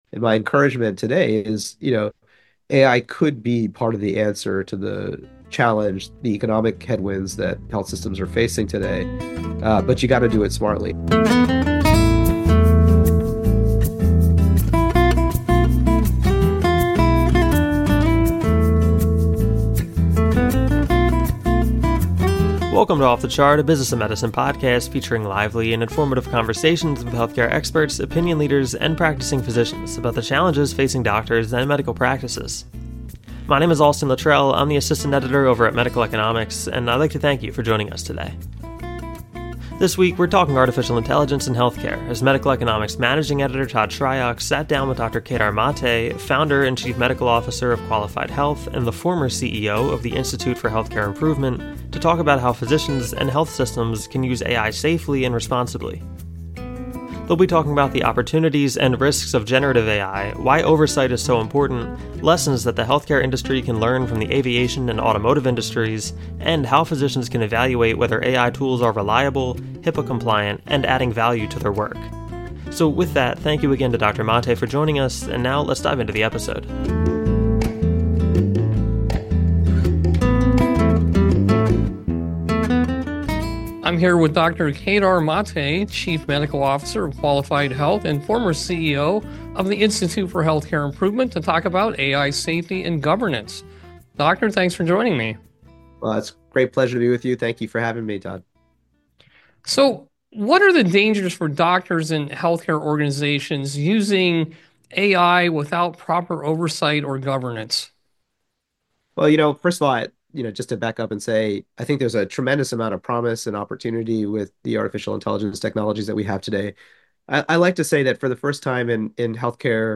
Introduction and Episode Overview (00:00:00) AI as a potential solution for health care’s economic challenges; importance of smart implementation. Podcast Introduction and Guest Introduction (00:00:22) Host and guest introductions; overview of episode topics: AI opportunities, risks, oversight and evaluation in health care. Start of Interview: AI Safety and Governance (00:01:23)